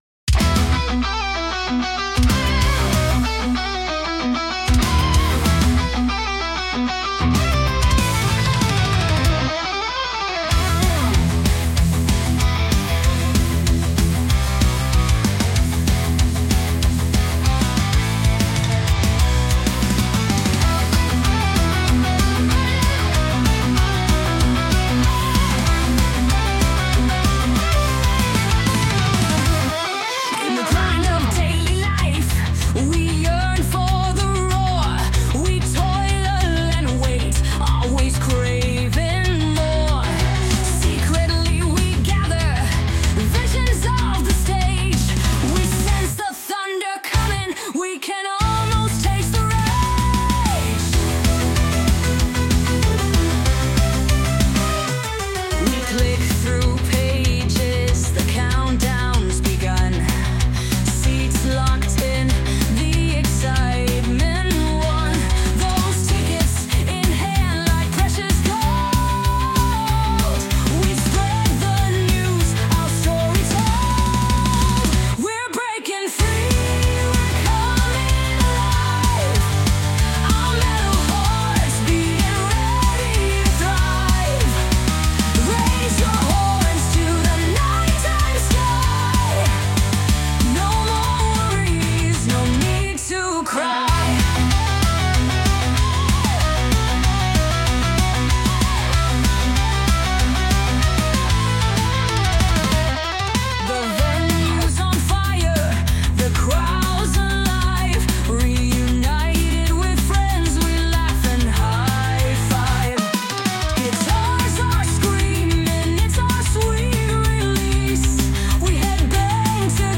Ein Heavy Metal Experiment